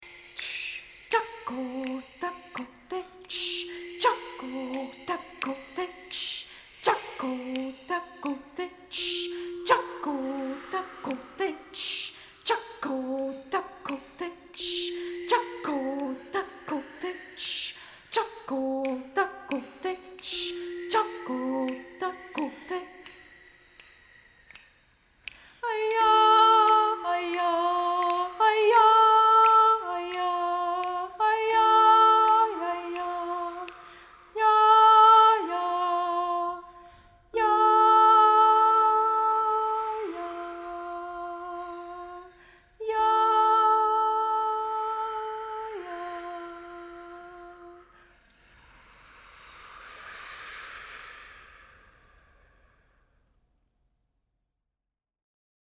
Chant thème de Choralies 2019, cette chanson toute nouvelle fait appel aux sons de la nature et des grands espaces de ntore pays.